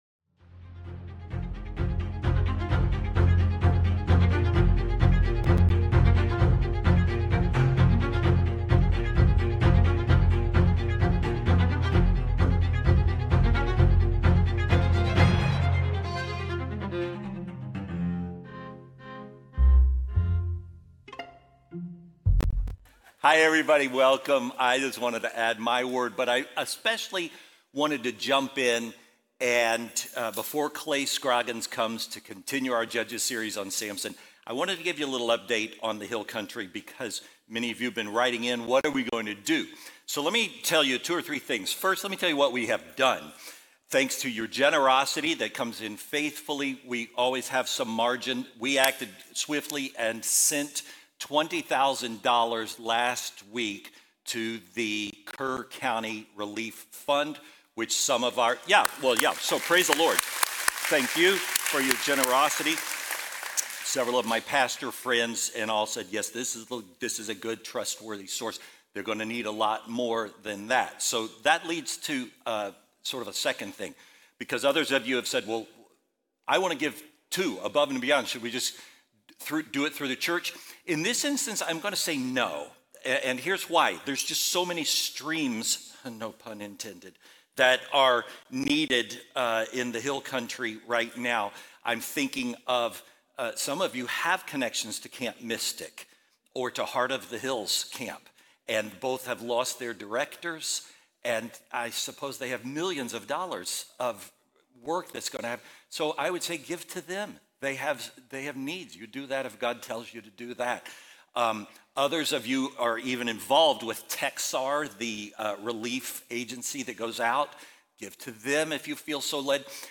Faithbridge Sermons Strength Without Surrender Jul 13 2025 | 00:33:47 1x 00:00 / 00:33:47 Subscribe Share Apple Podcasts Spotify Overcast RSS Feed Share Link Embed